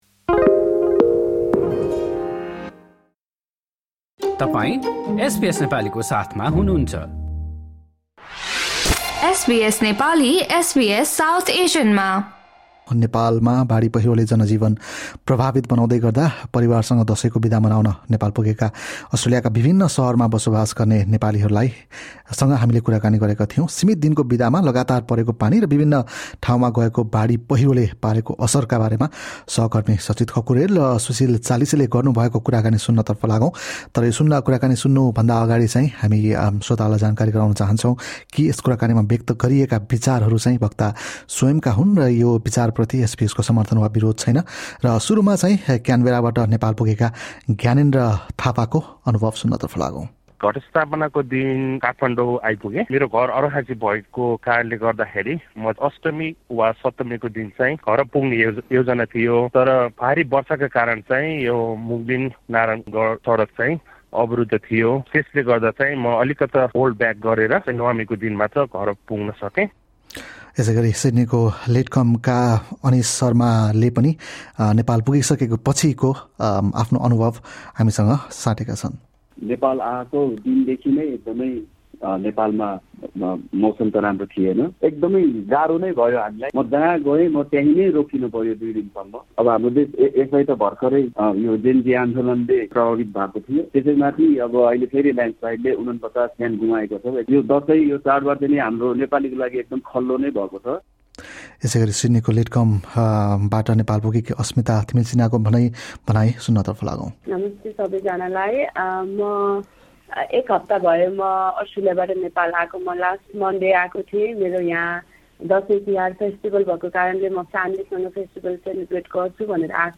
नेपालमा बाढी पहिरोले जनजीवन प्रभावित बनाउँदै गर्दा, परिवारसँग दशैंको बिदा मनाउन हाल नेपाल पुगेका अस्ट्रेलियामा बसोबास गर्ने नेपालीभाषीहरूसँग, लगातार परेको पानी र बाढी पहिरोले पारेको असरबारे एसबीएस नेपालीले गरेको कुराकानी सुन्नुहोस्।